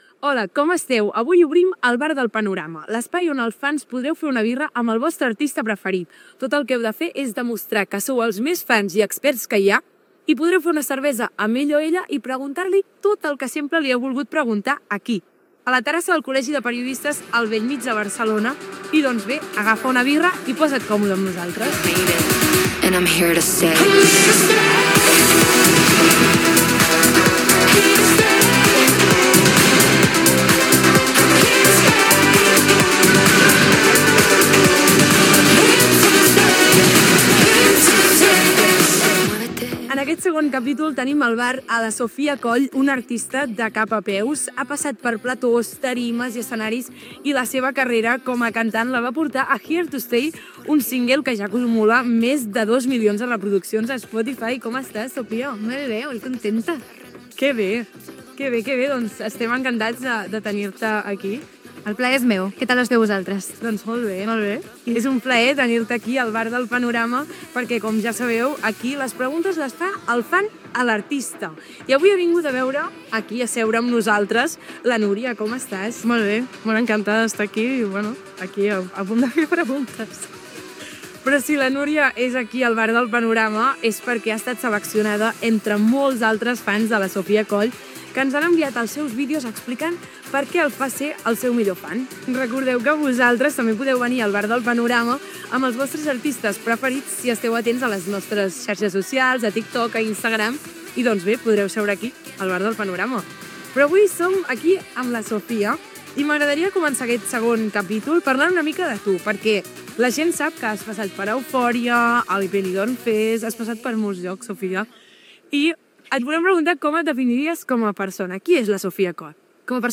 Salutació, presentació i entrevista
Entreteniment